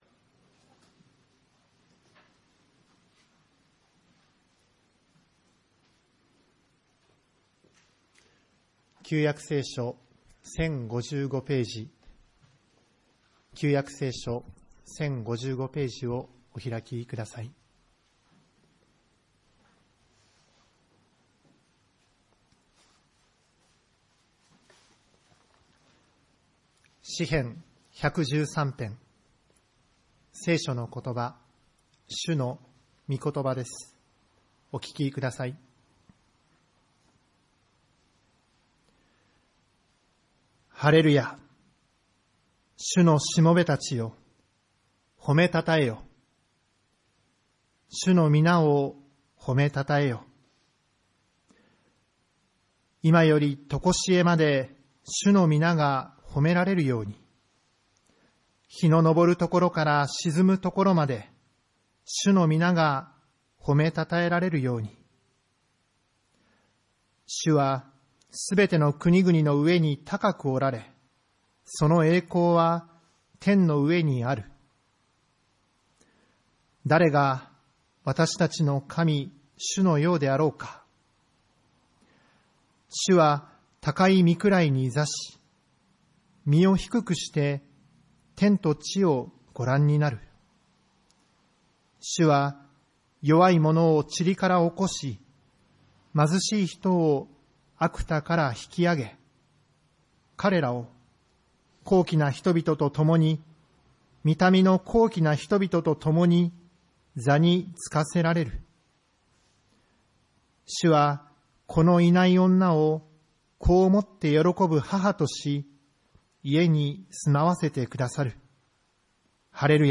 礼拝メッセージ